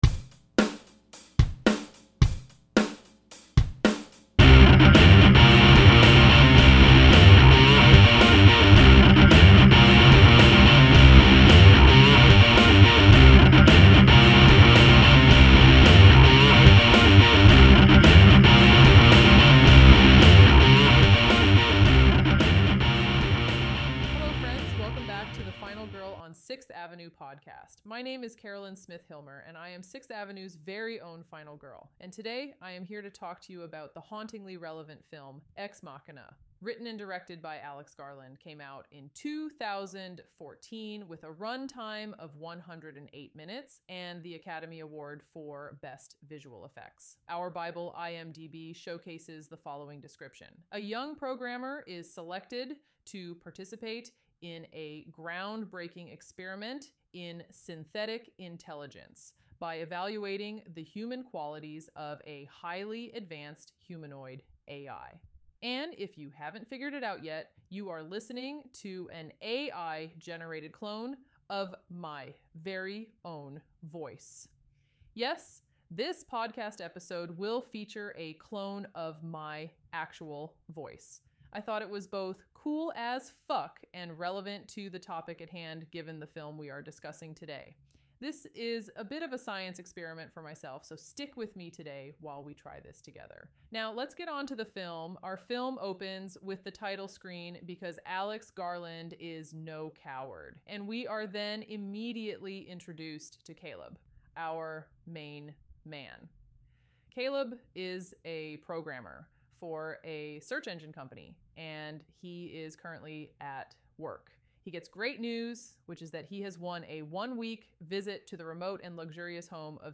** this podcast episode was generated using an AI cloned version of my voice using ElevenLabs **